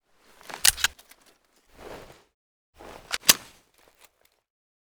fn57_magcheck.ogg